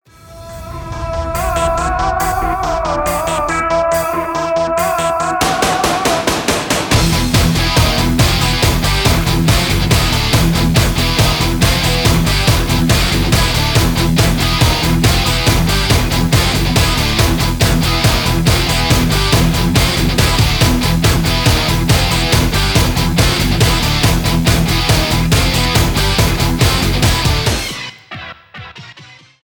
Рок рингтоны
industrial metal